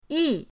yi4.mp3